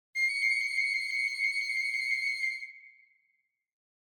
Download Free Cartoon Comedy Sound Effects | Gfx Sounds
Cartoon-funny-moment.mp3